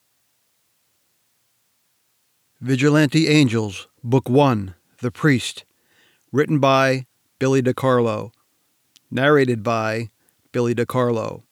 That meets ACX Technical Conformance. Peaks quieter than 3dB, Noise quieter than -60dB and RMS (loudness) between -18dB and -23dB.